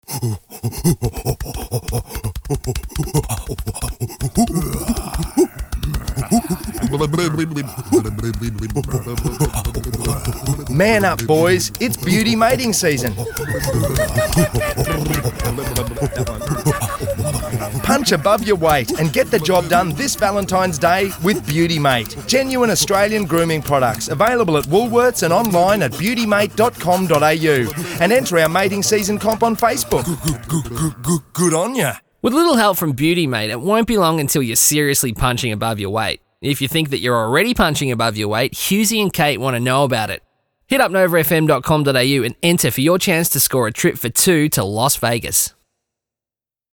Monkey noises run at the start of the radio ad on Nova, with the line “Man up, it’s beauty mating season”.